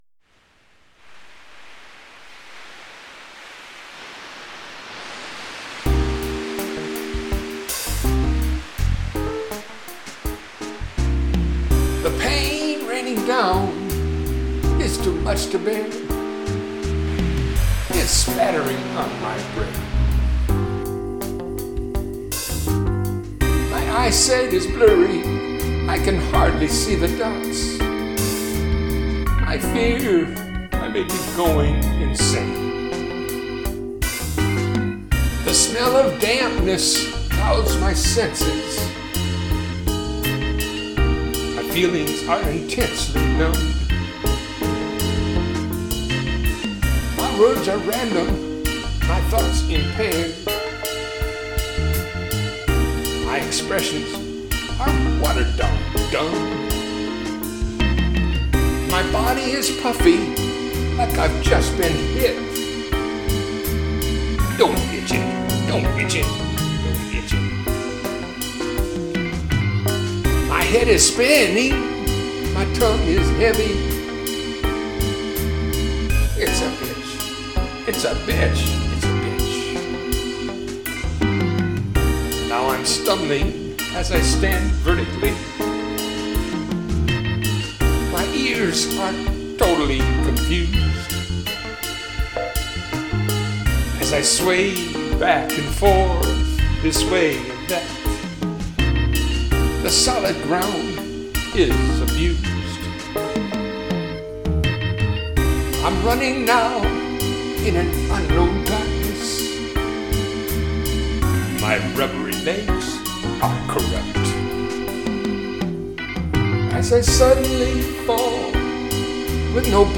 Pain is on the blues side.